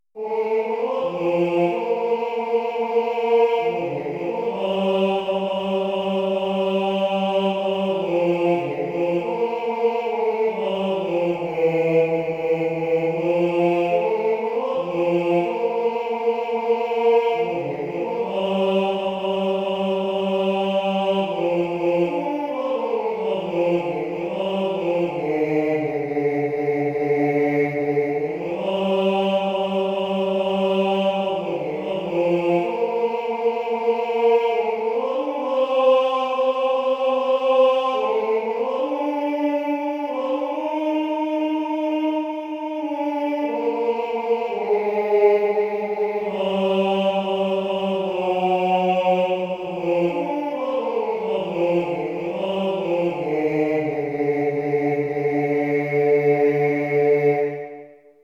gotland_man.mp3